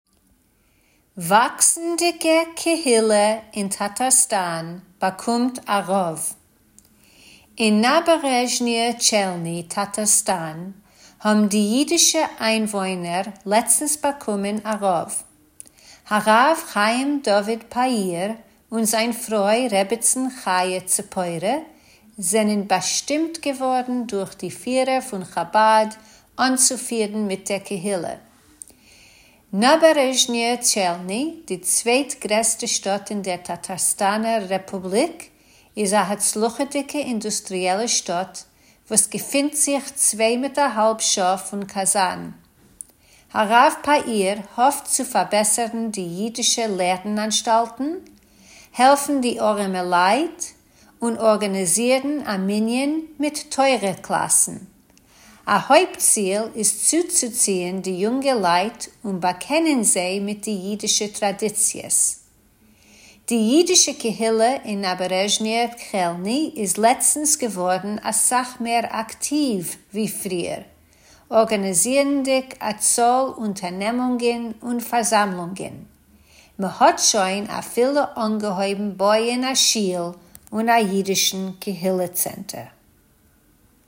Tidbits is a bi-weekly feature of easy news briefs in Yiddish that you can listen to or read, or both!